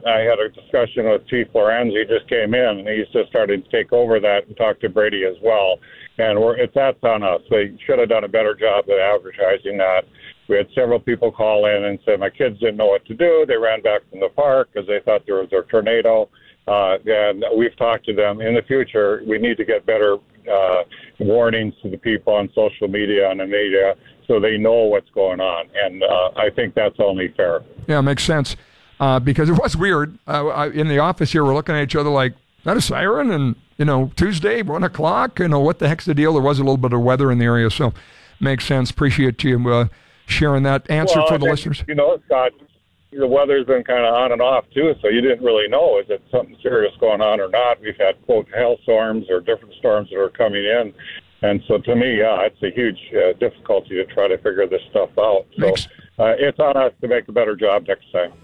“It’s on us to do a better job next time,” Mahoney told The Flag during a live phone interview on Wednesday morning.